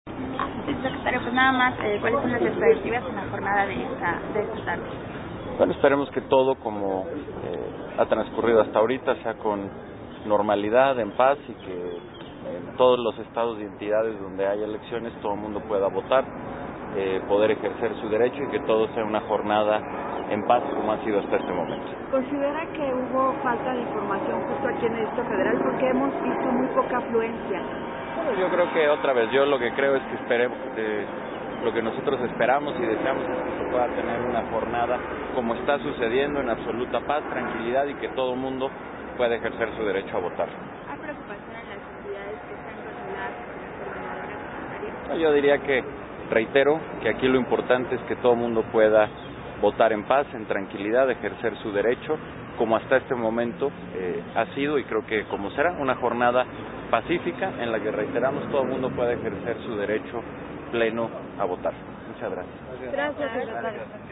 AUDIO-Declaración-del-secretario-de-Educación-Pública-Aurelio-Nuño-Mayer-después-de-emitir-su-voto.mp3